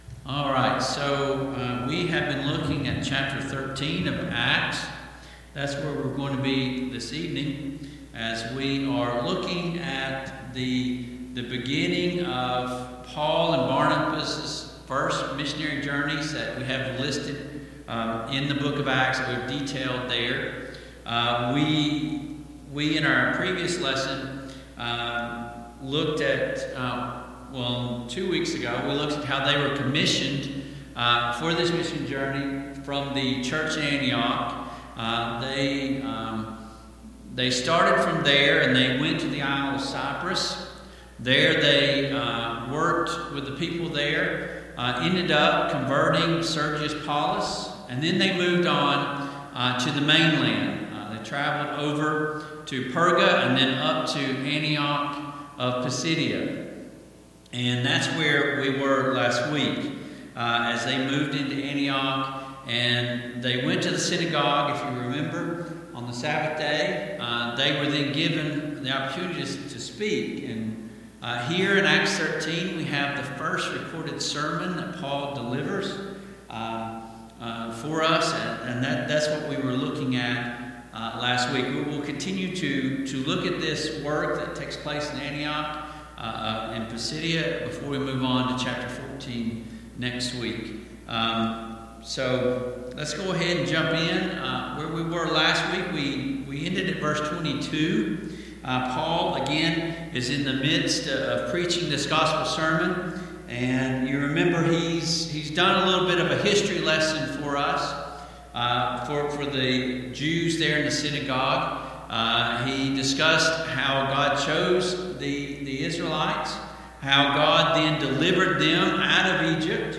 Passage: Acts 13:23-39 Service Type: Mid-Week Bible Study